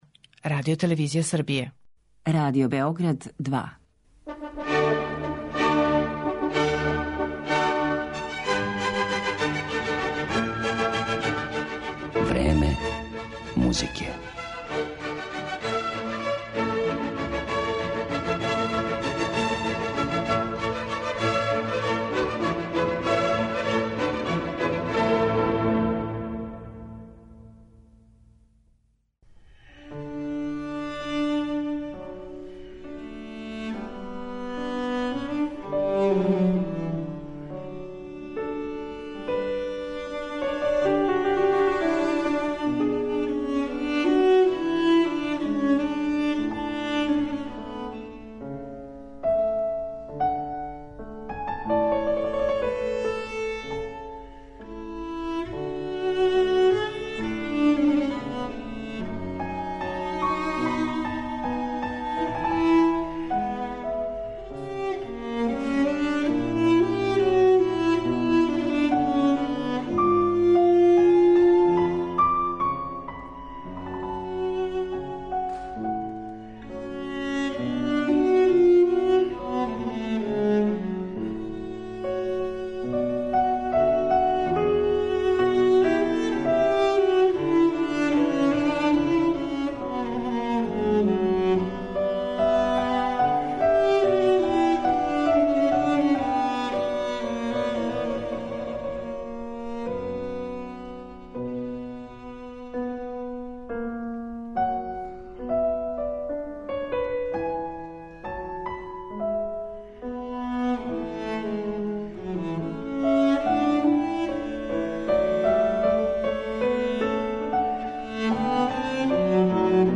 виолончело
Данијела Милер-Шота представићемо кроз његове интерпретације дела Јохана Себастијана Баха, Роберта Шумана и Лудвига ван Бетовена, остварене са пијанисткињом Анђелом Хјуит.